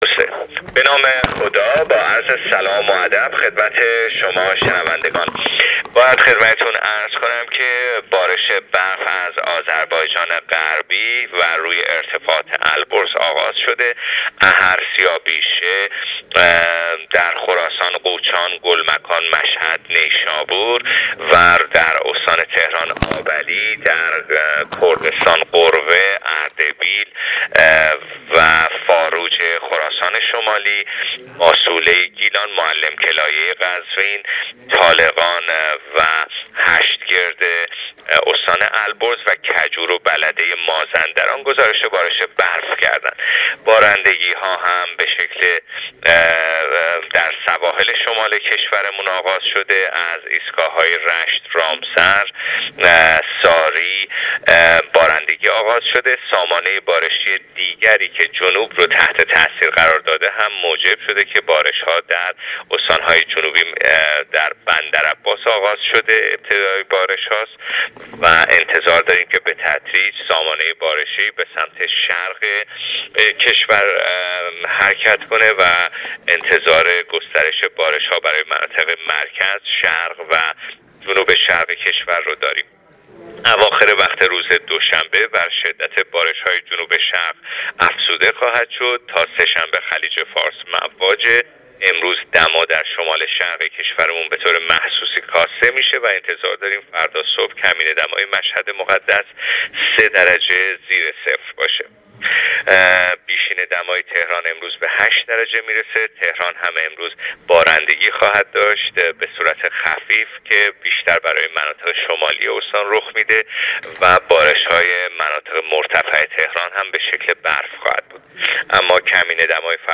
بشنوید: آخرین وضعیت آب و هوا در کشور از زبان کارشناس هواشناسی